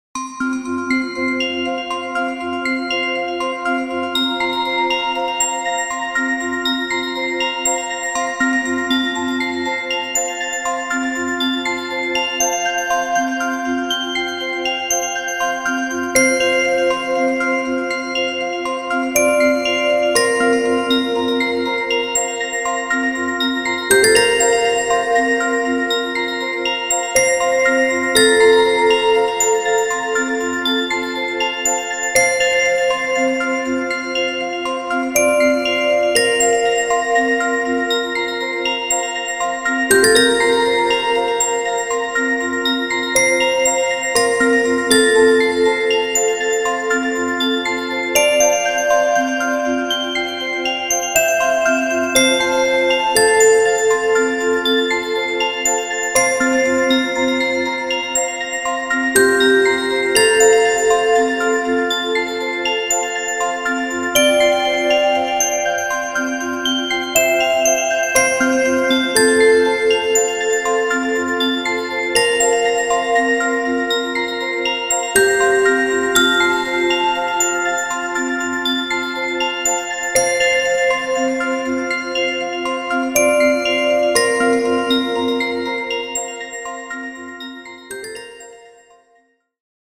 フリーBGM イベントシーン 幻想的・神秘的
フェードアウト版のmp3を、こちらのページにて無料で配布しています。